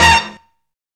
SOFT HIT.wav